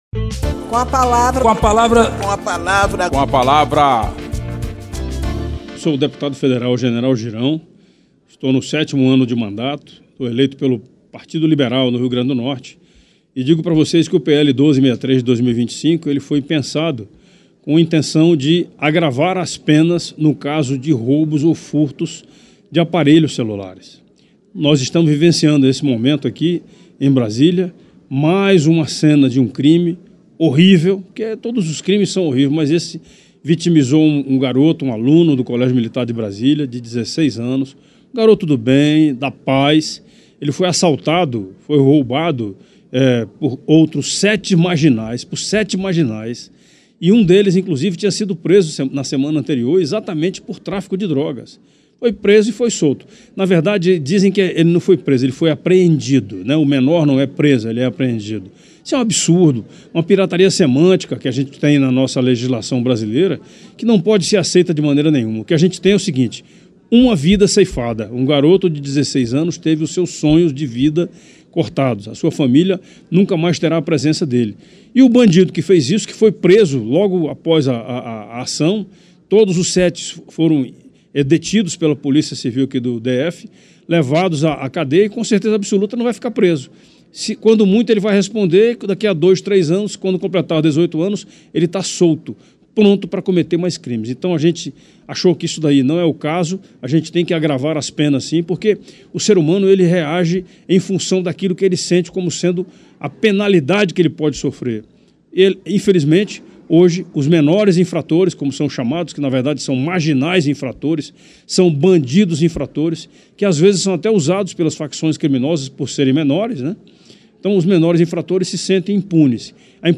Espaço aberto para que cada parlamentar apresente aos ouvintes suas propostas legislativas